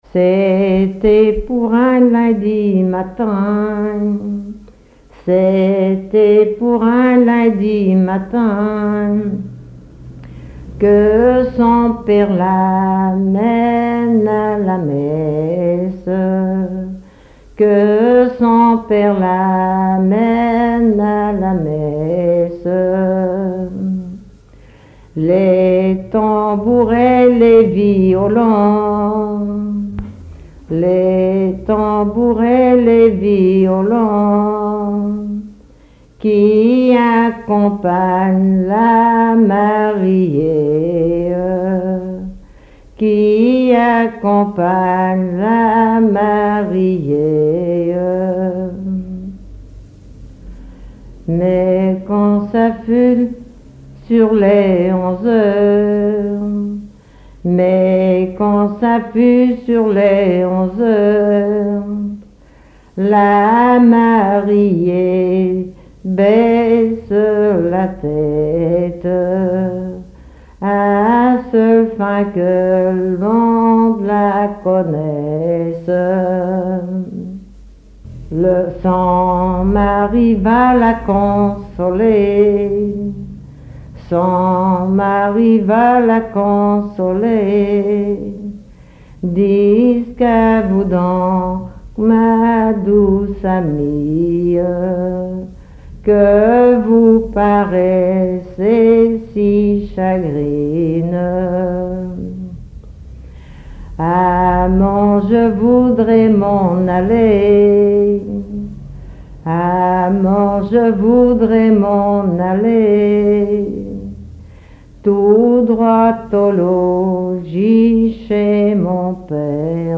collecte en Vendée
Témoignages et chansons traditionnelles
Pièce musicale inédite